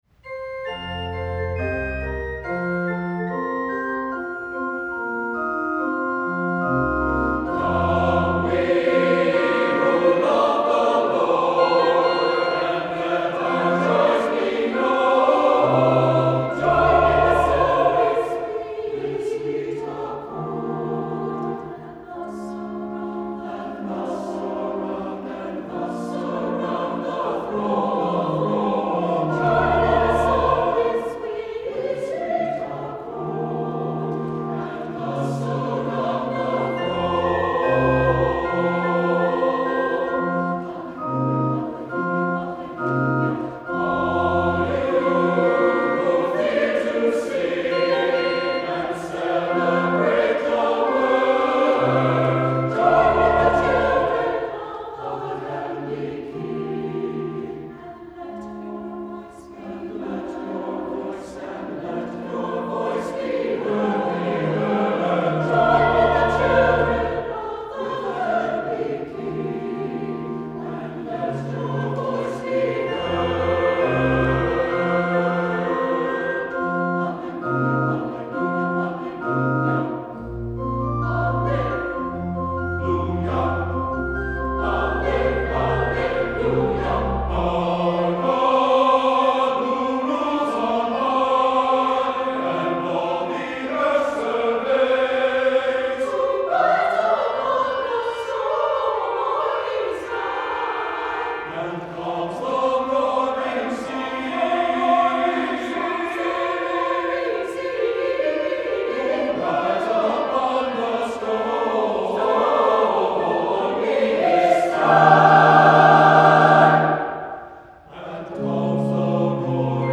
for SATB Chorus and Organ (2009)
This is a joyous, energetic song.